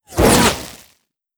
wateryzap2b.wav